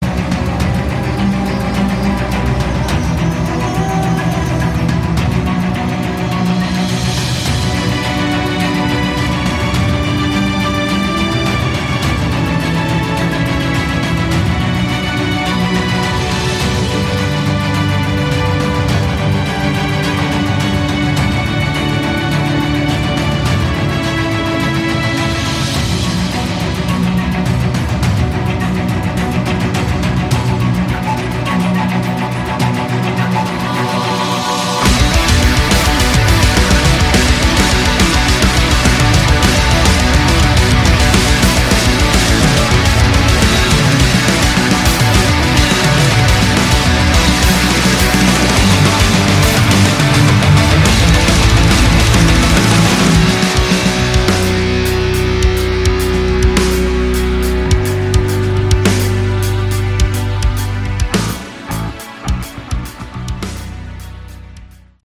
Category: Melodic Metal
guitars
vox
keyboards
bass
drums